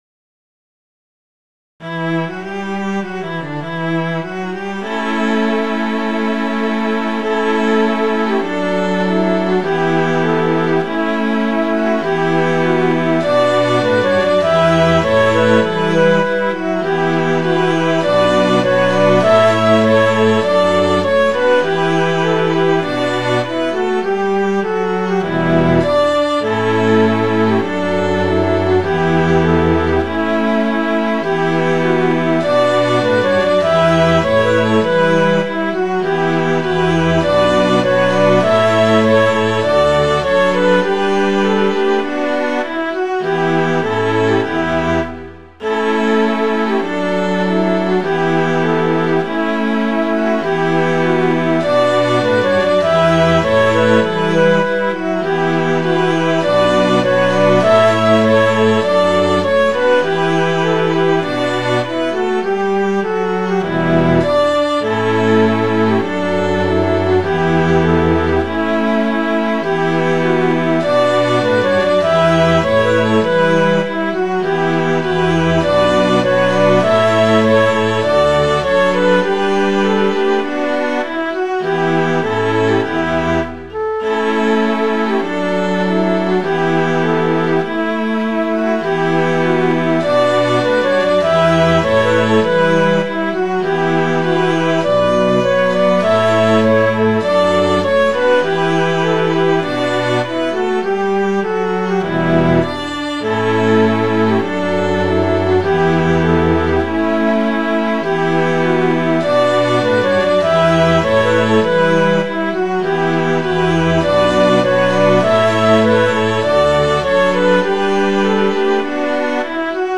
Midi File, Lyrics and Information to Through Moorfields